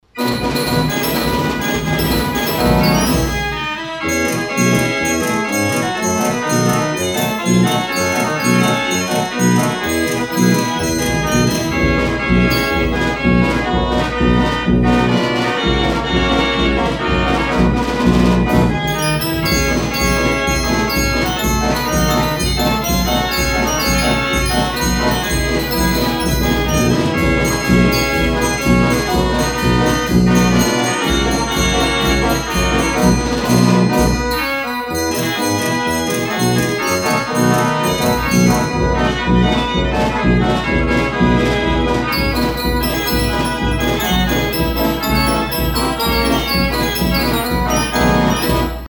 Carousel Fair Organ
her music is very exhilarating